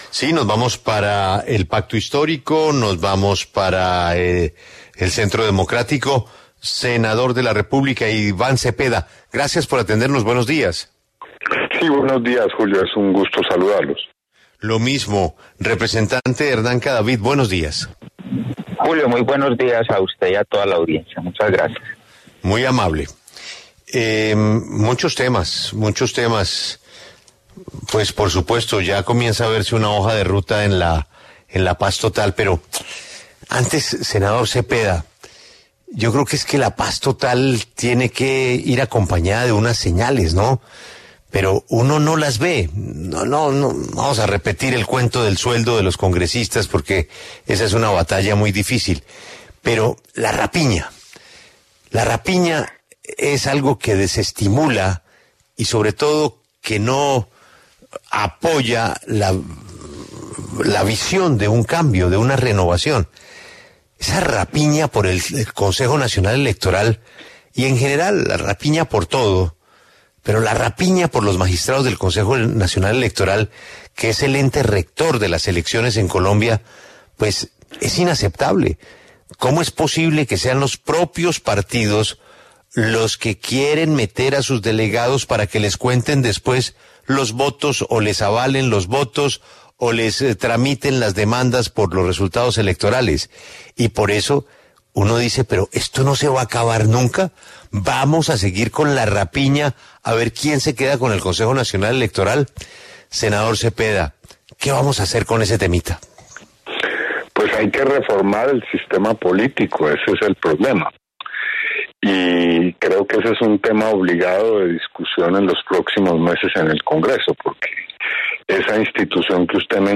Iván Cepeda y Hernán Cadavid hablan sobre proyecto de ley para buscar la “paz total”
En diálogo con La W, el senador del Pacto Histórico, Iván Cepeda, aseguró que la paz se convertirá en un tema de Estado y no en una política subsidiaria del Gobierno.